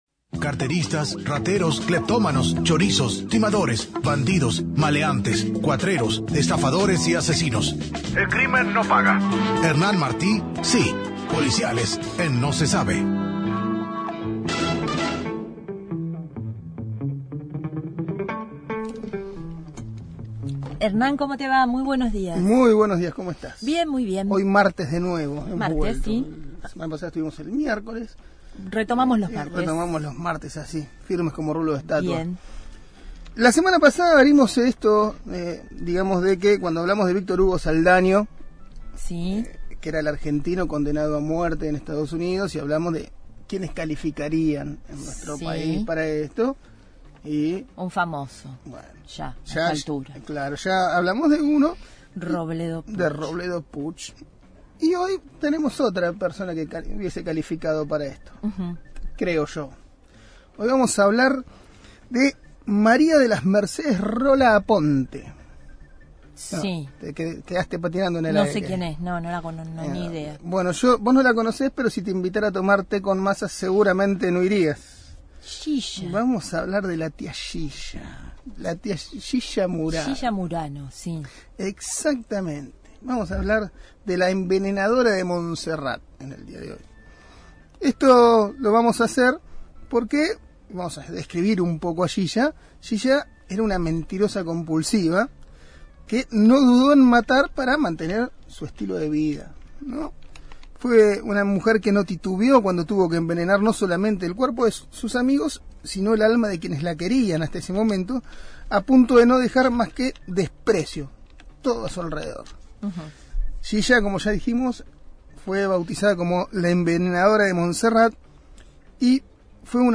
Columna de policiales